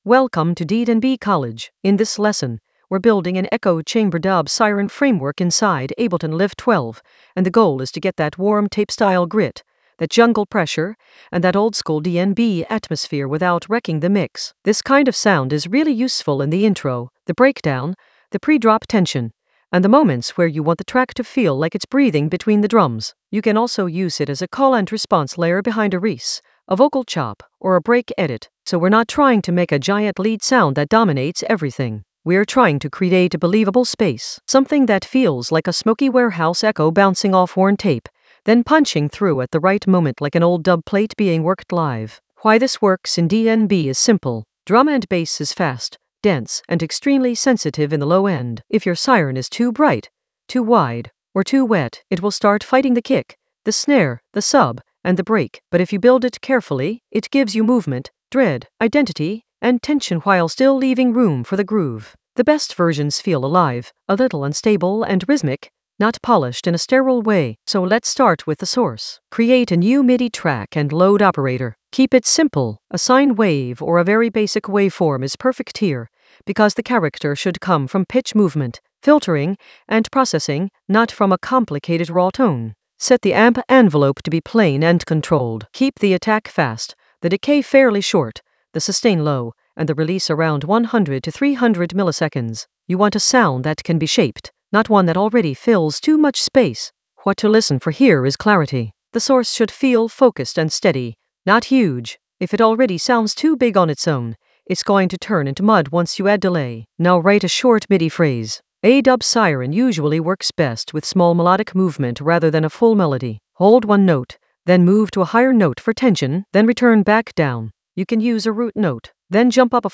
An AI-generated beginner Ableton lesson focused on Echo Chamber Ableton Live 12 a dub siren framework blueprint for warm tape-style grit for jungle oldskool DnB vibes in the Atmospheres area of drum and bass production.
Narrated lesson audio
The voice track includes the tutorial plus extra teacher commentary.